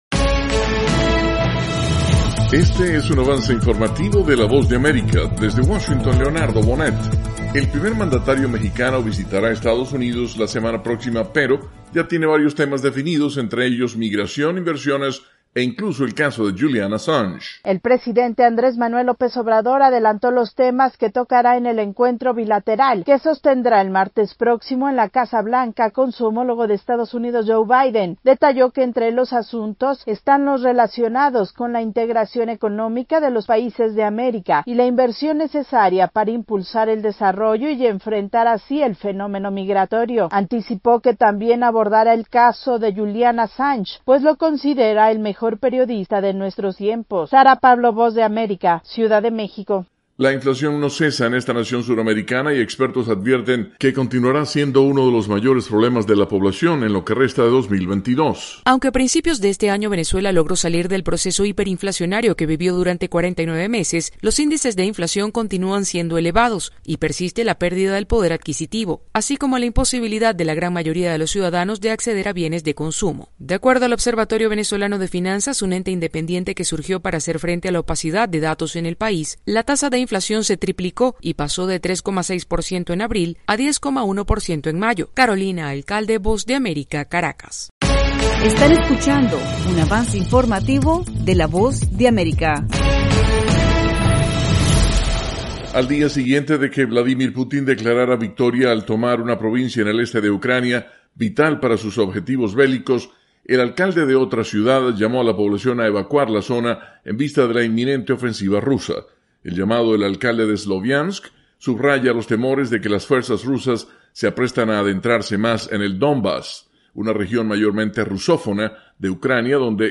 Avance Informativo - 3:00 PM